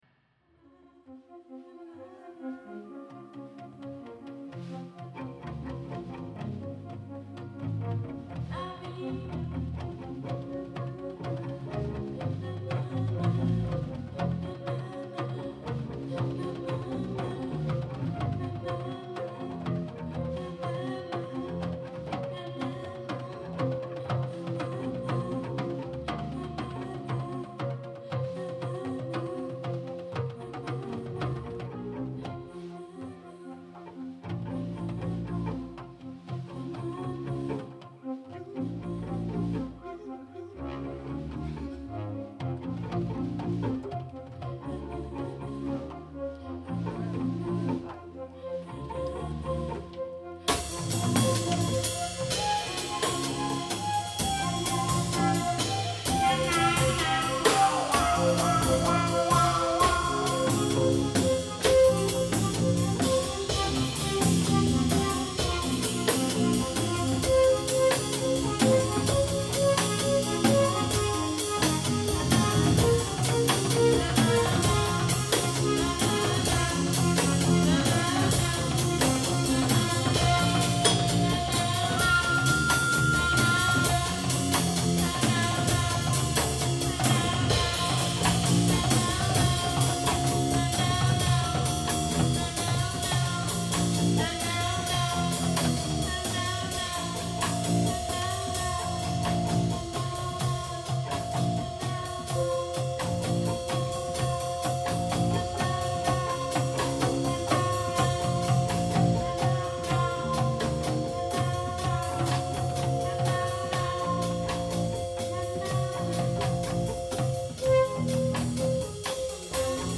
Live jam-session in Riva San Vitale (CH)